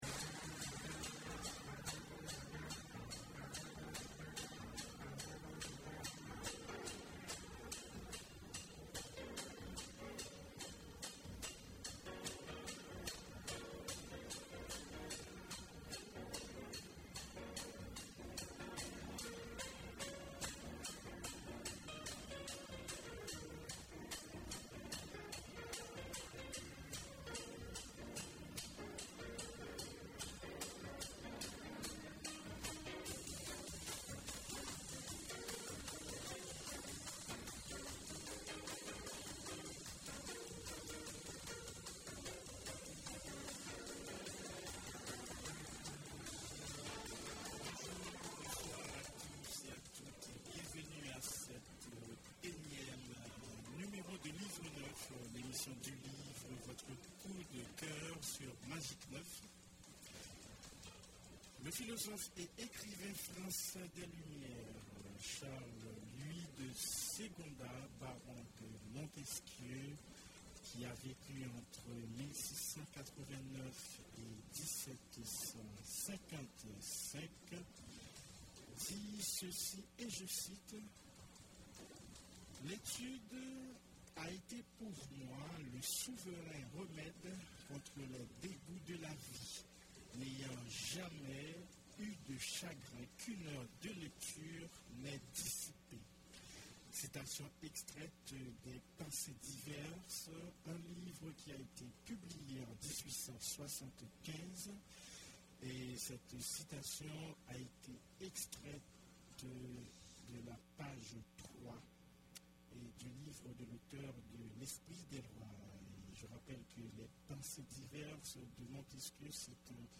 Invités: Yanick Lahens et Evelyne Trouillot